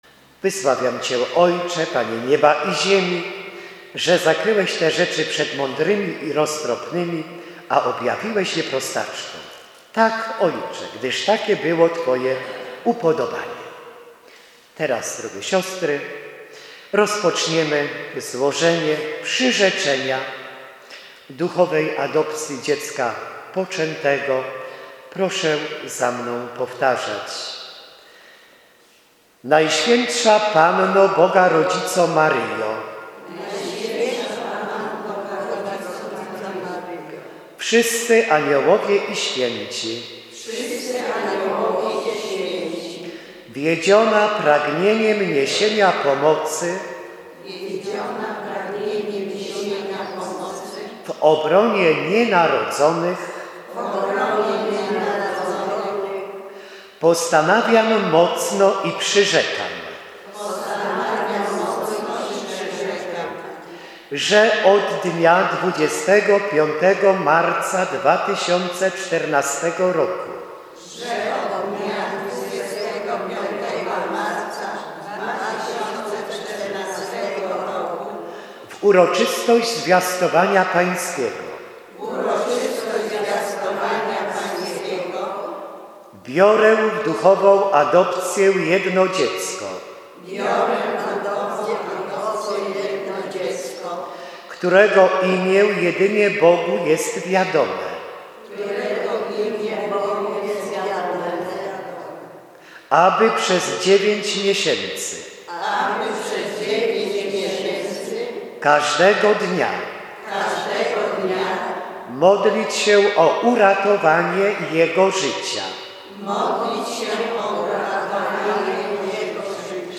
przyrzeczenie.mp3